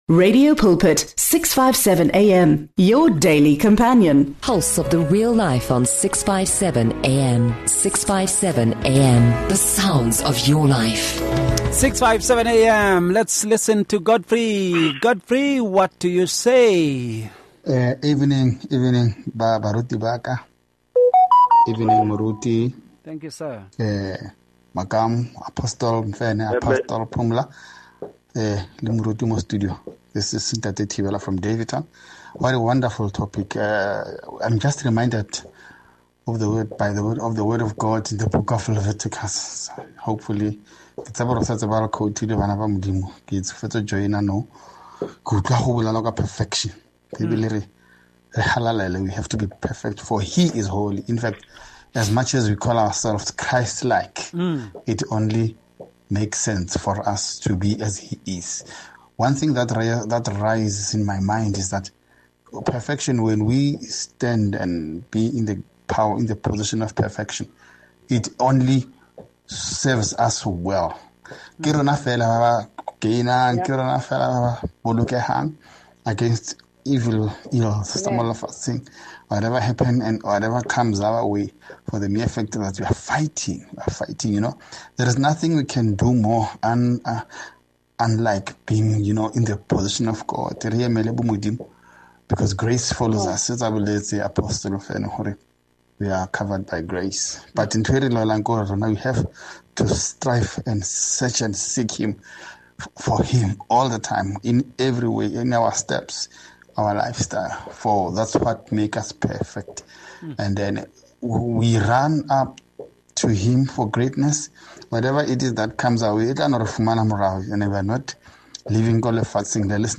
They explore perfection as a state of divine excellence that reflects the Creator’s intent. This dialogue aims to deepen understanding of spiritual perfection beyond worldly definitions.